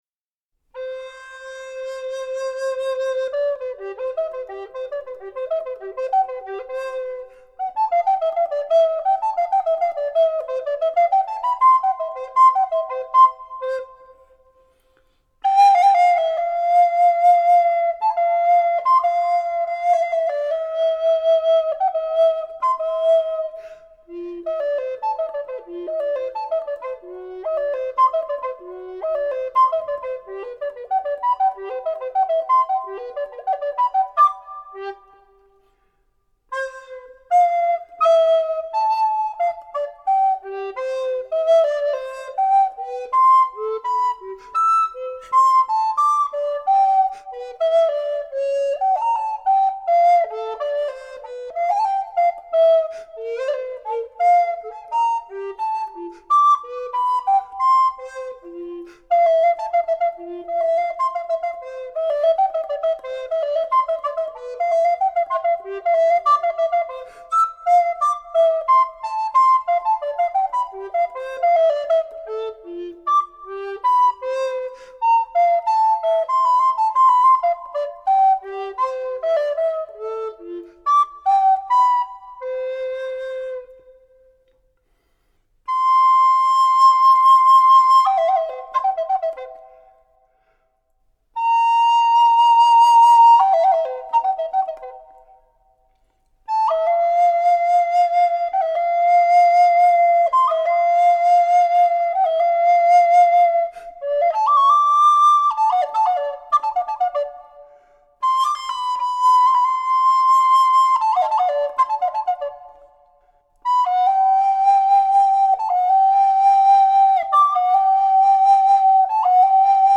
Blockflötenstücke, gespielt auf Dolmetsch Altblockflöte Palisander
Georg Philipp Telemann Fantasien für Altblockflöte solo